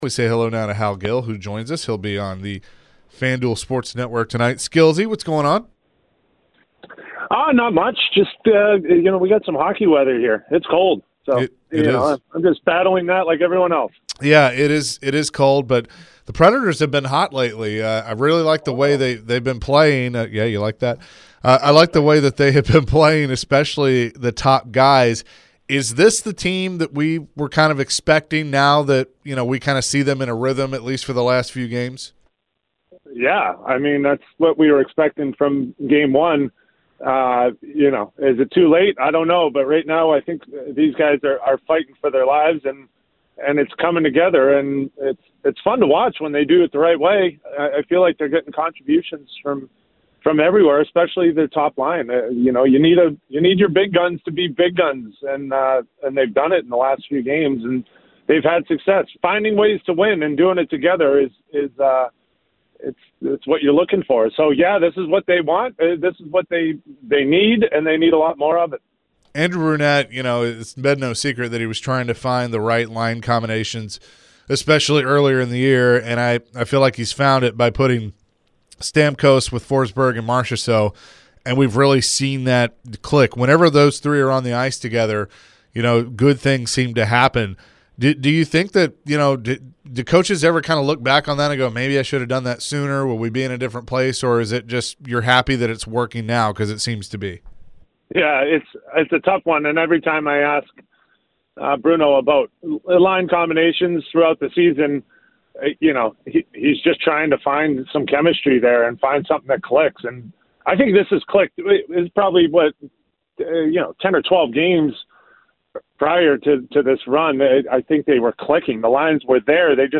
Nashville Predators radio/TV analyst Hal Gill joined the show to discuss the Nashville Predators' winning streak and their game this evening vs the Sharks. Can the Preds make it four wins in a row?